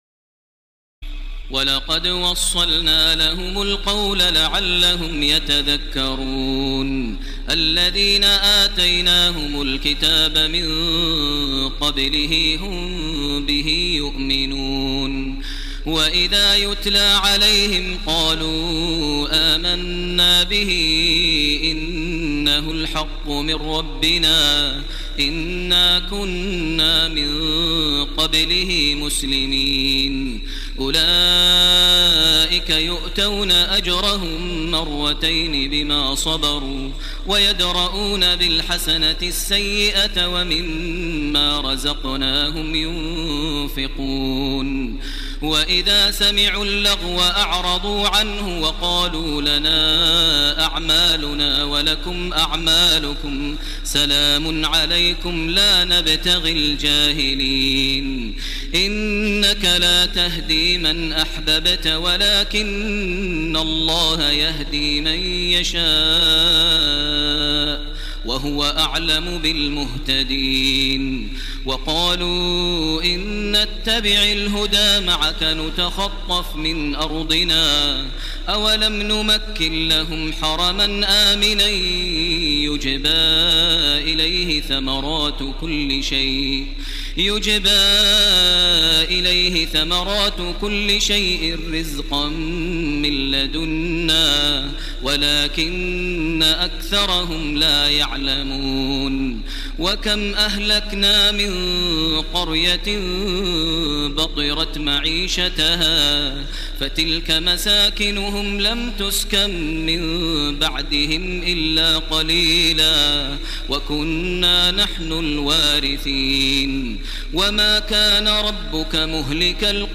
تراويح الليلة العشرون رمضان 1430هـ من سورتي القصص (51-88) والعنكبوت (1-45) Taraweeh 20 st night Ramadan 1430H from Surah Al-Qasas and Al-Ankaboot > تراويح الحرم المكي عام 1430 🕋 > التراويح - تلاوات الحرمين